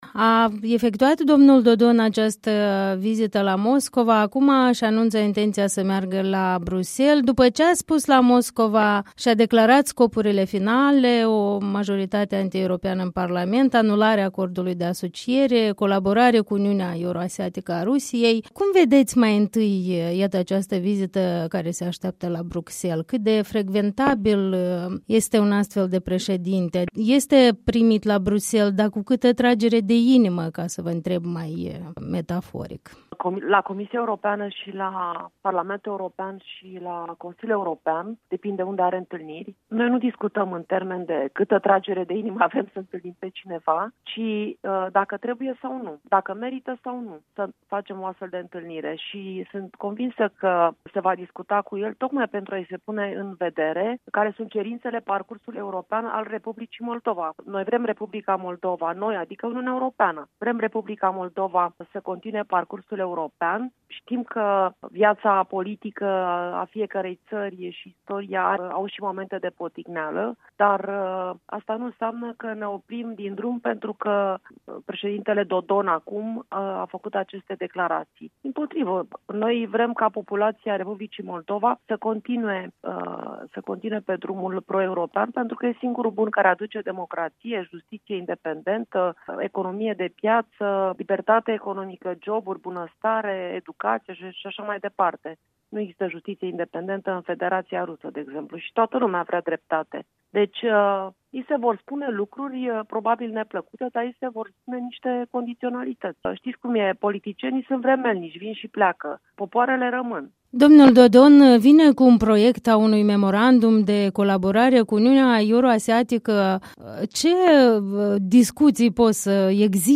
Interviu cu Monica Macovei